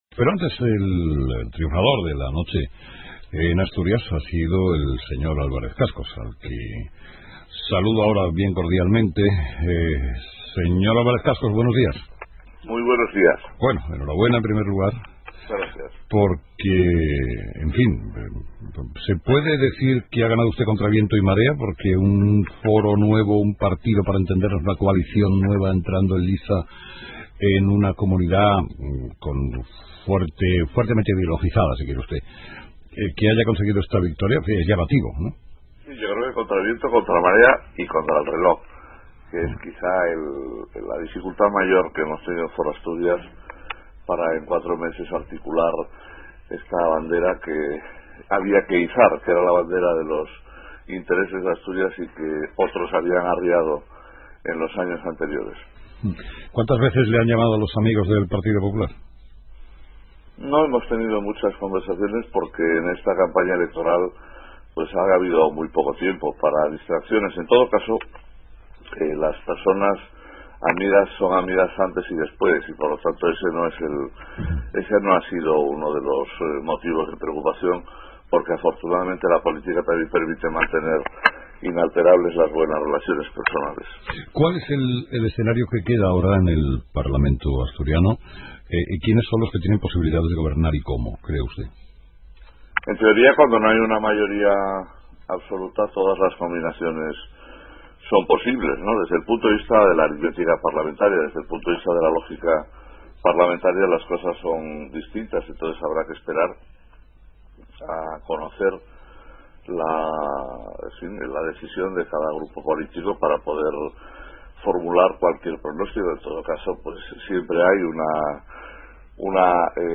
Entrevistado: "Francisco Álvarez Cascos"